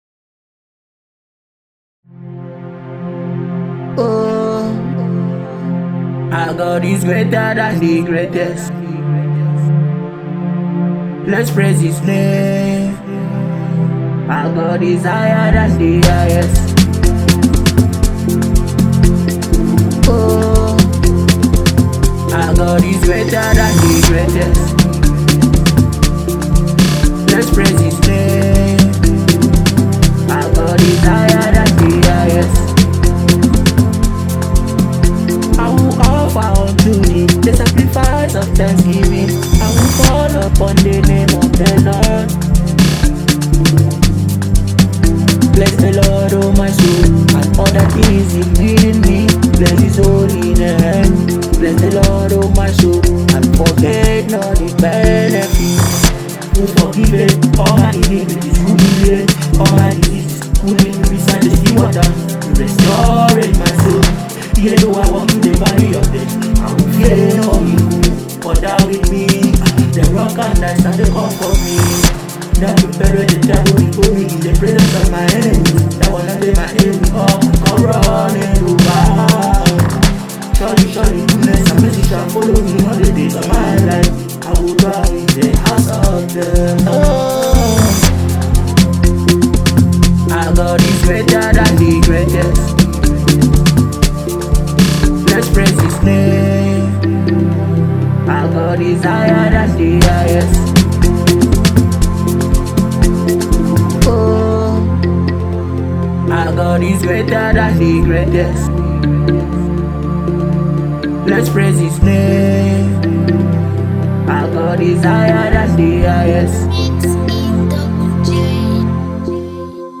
multitalented award-winning gospel minister & singer
a powerful kingdom tune